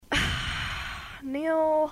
Sigh